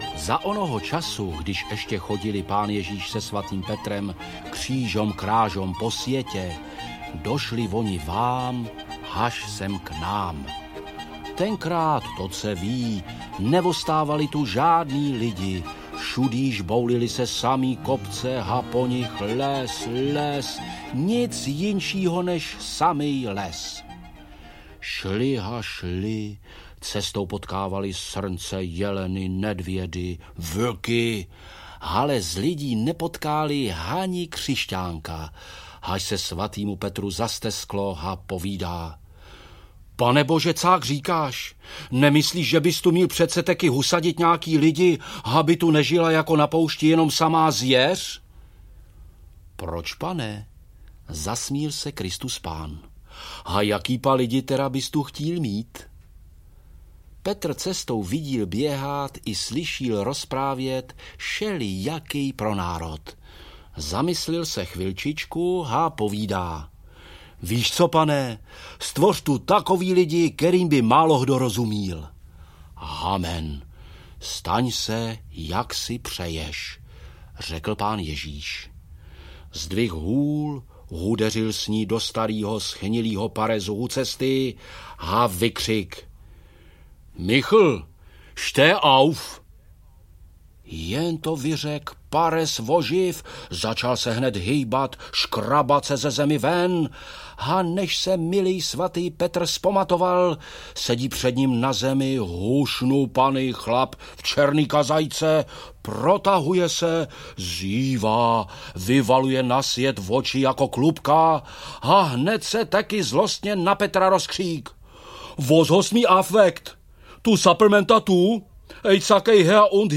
Chodské pohádky audiokniha
Ukázka z knihy